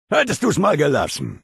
Audiodialoge